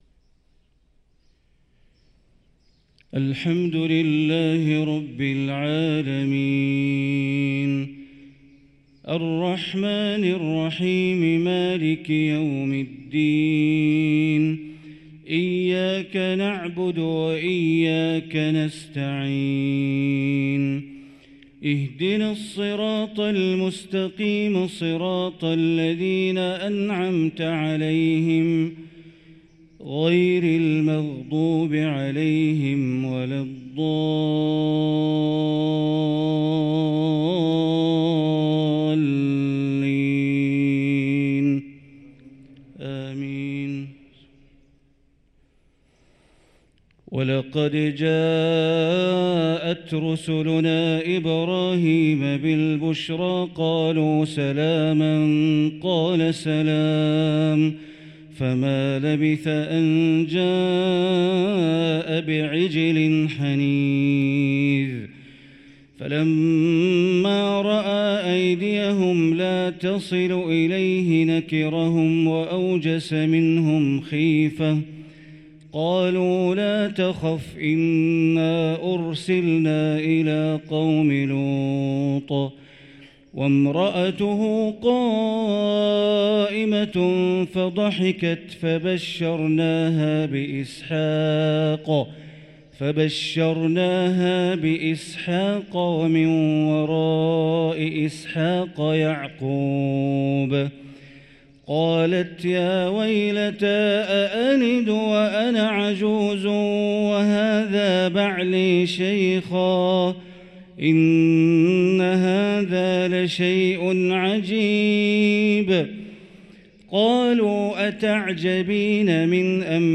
صلاة الفجر للقارئ بندر بليلة 15 صفر 1445 هـ
تِلَاوَات الْحَرَمَيْن .